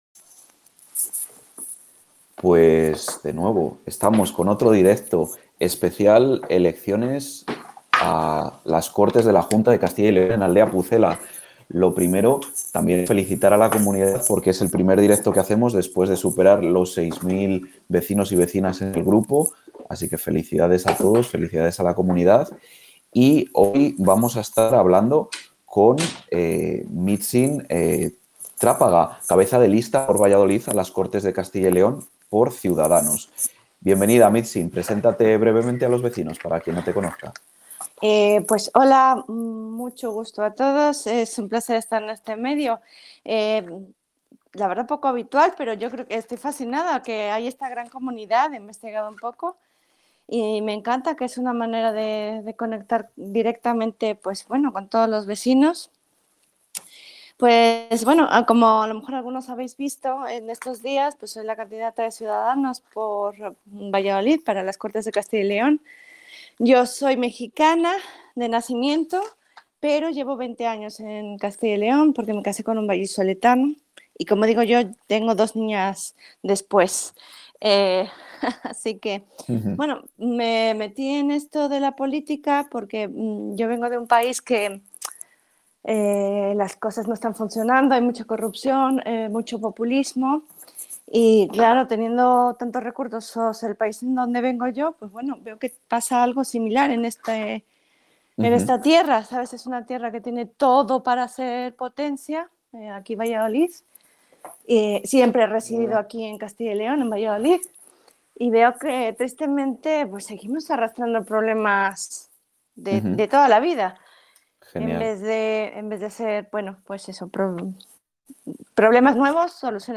Continuamos nuestros Directos con los candidatos a las elecciones de la Junta 2026.